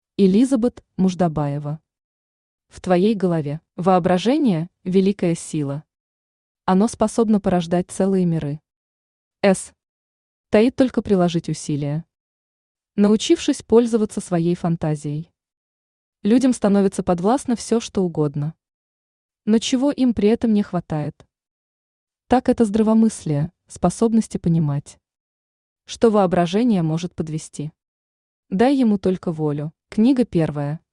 Аудиокнига В твоей голове | Библиотека аудиокниг
Aудиокнига В твоей голове Автор Элизабет Муждабаева Читает аудиокнигу Авточтец ЛитРес.